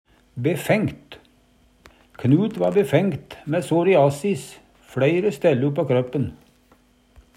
befengt - Numedalsmål (en-US)